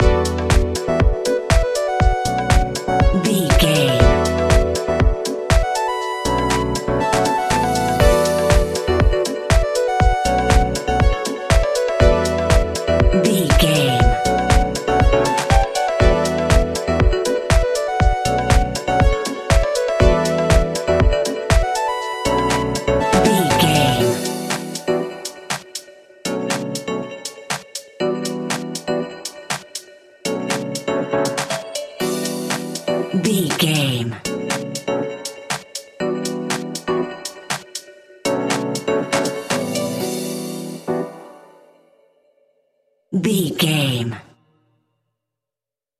Dorian
groovy
uplifting
energetic
drum machine
synthesiser
funky house
upbeat
funky guitar
clavinet
synth bass
horns